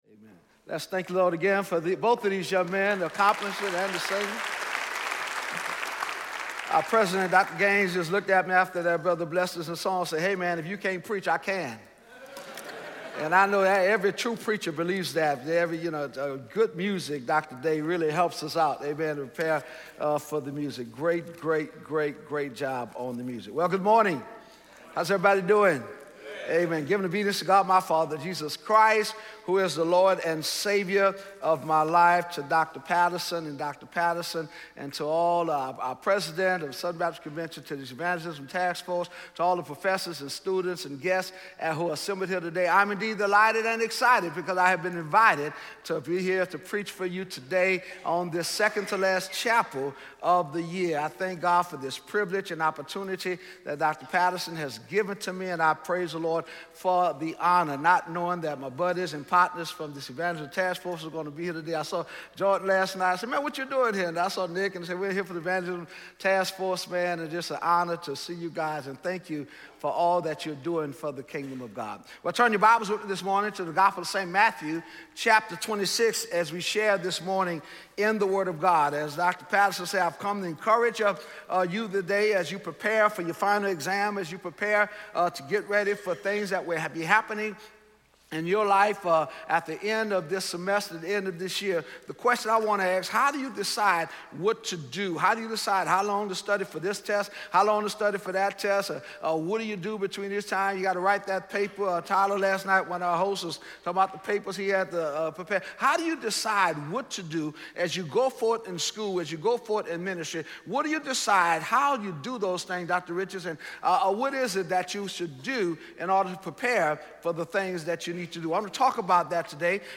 Dr. Fred Luter speaking on Mathew 26:36-46 in SWBTS Chapel on Tuesday December 5, 2017
SWBTS Chapel Sermons Fred Luter - When You Dont Know What To Do Play Episode Pause Episode Mute/Unmute Episode Rewind 10 Seconds 1x Fast Forward 30 seconds 00:00 / Subscribe Share RSS Feed Share Link Embed